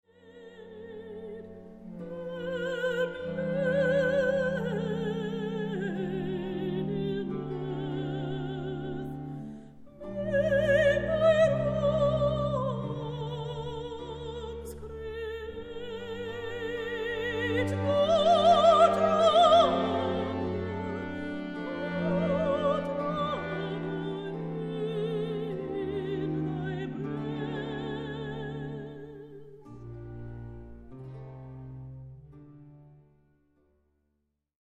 ravishing Australian soprano
Soprano